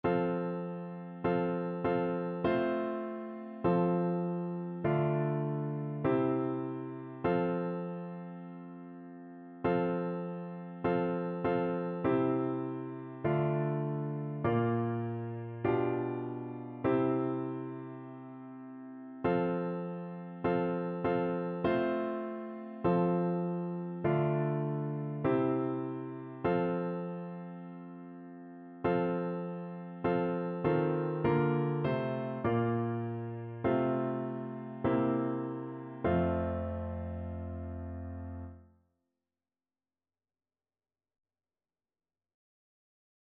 No parts available for this pieces as it is for solo piano.
F major (Sounding Pitch) (View more F major Music for Piano )
2/2 (View more 2/2 Music)
Instrument:
Piano  (View more Intermediate Piano Music)
Christian (View more Christian Piano Music)
teach_me_o_lord_PNO.mp3